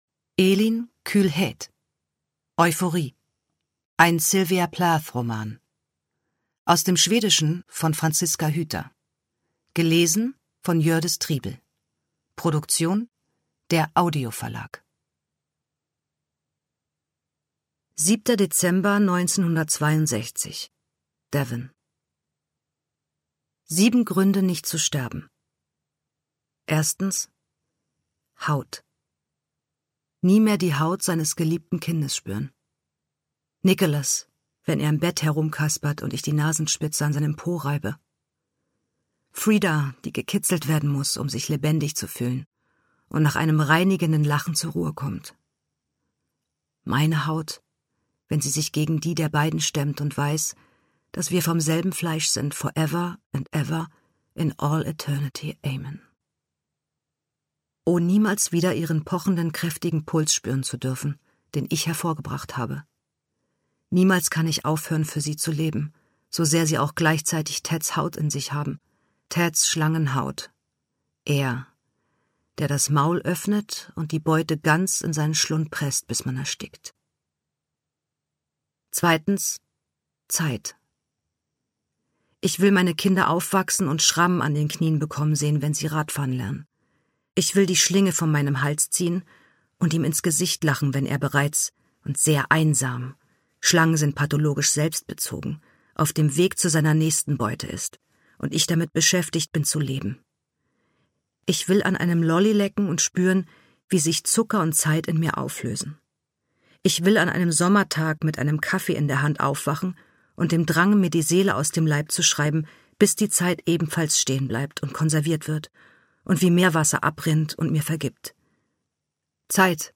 Ungekürzte Lesung mit Jördis Triebel (1 mp3-CD)
Jördis Triebel (Sprecher)